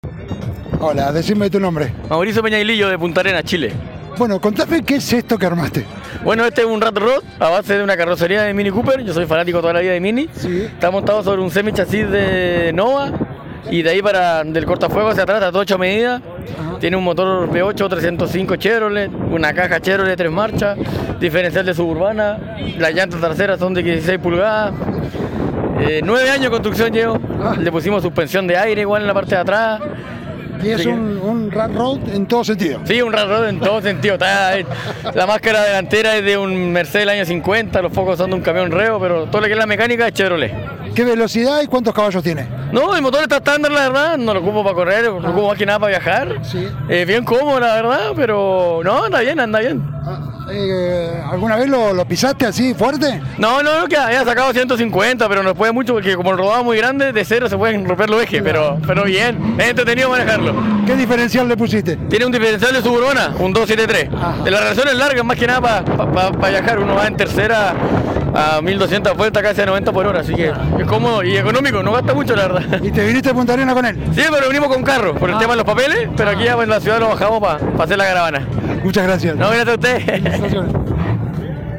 Rio Grande 19/10/2024.- Este sábado se vivió una jornada con mucho ruido de motores, escapes libres y autos que ya son clásicos. El encuentro tuvo lugar en el Parque del Centenario de nuestra ciudad y fue digno de ver.